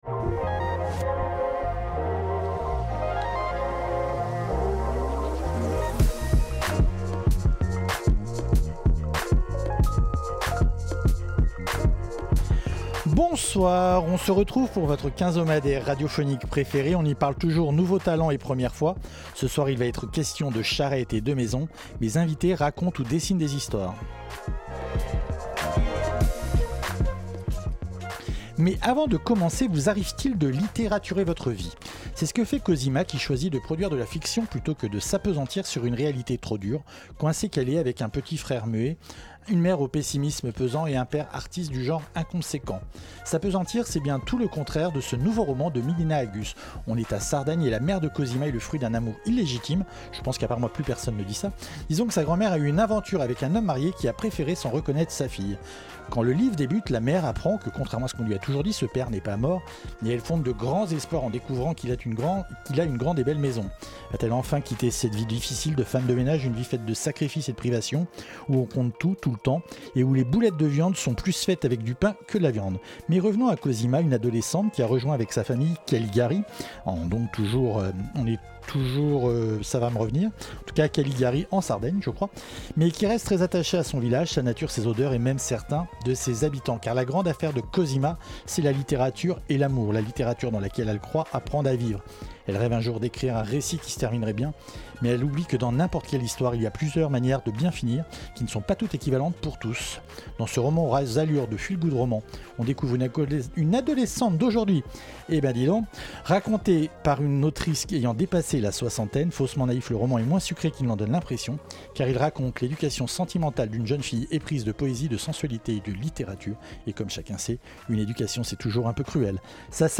(atttention charrette avec 2 R et chariot un seul) En raison d'un incident d'enregistrement, il manque quelques minutes de l'émission.
Type Entretien